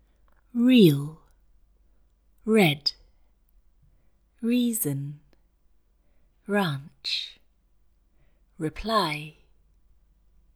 The British RP /r/ is pronounced in a vowel-like way with tension in the tongue.